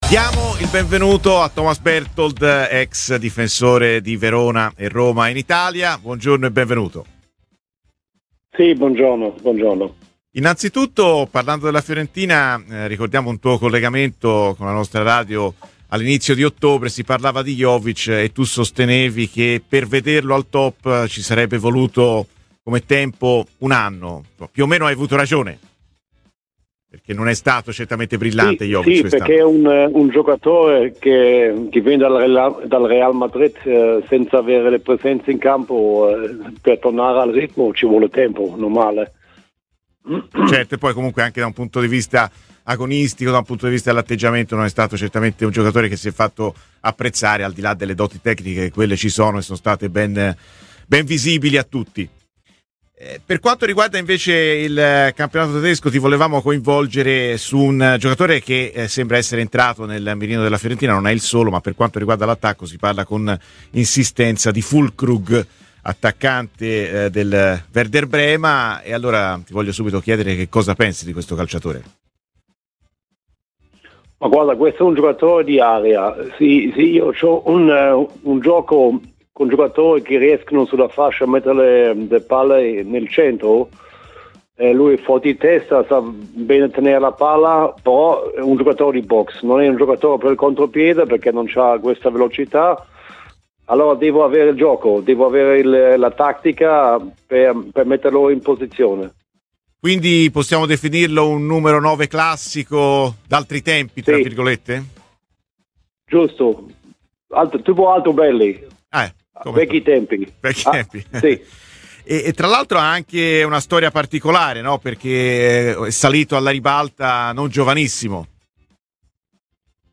Thomas Berthold, ex giocatore tedesco con un passato in Italia al Verona e alla Roma, si è così espresso a "Viola Amore Mio" su Radio FirenzeViola sull'obiettivo viola Niclas Füllkrug: "E' forte di testa, tiene bene la palla. Non è però per il contropiede, va servito sulla posizione: è un Altobelli di oggi. Ha già una certa età, anche se il profilo è interessante specie se Italiano cerca il classico numero 9. Vedendolo al Werder, ha dato il meglio quando è stato servito in area e non per fare sponde: lui pensa a buttarla dentro".